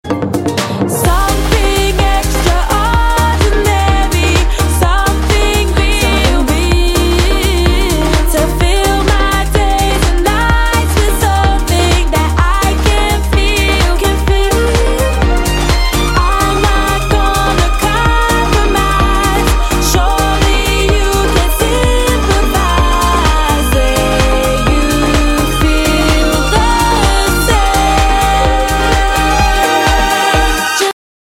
Солнечная и светлая мелодия.